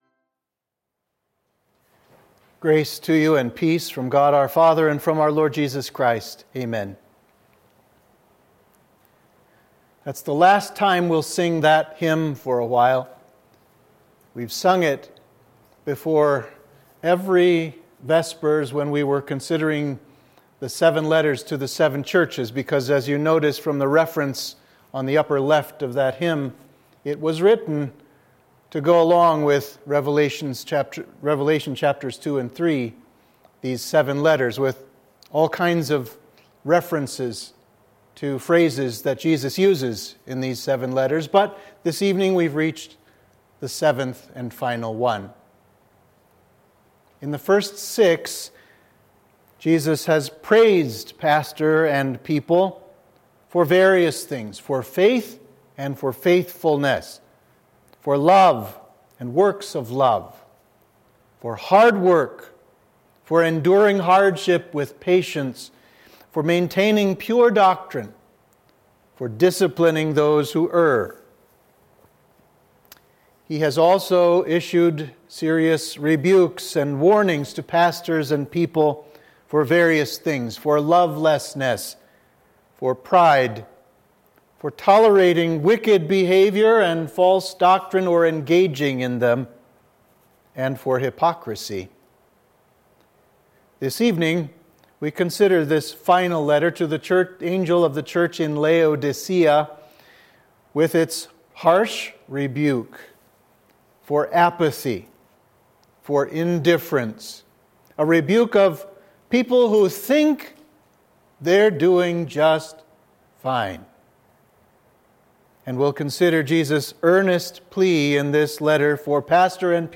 Sermon for Midweek of Reminiscere – Lent 2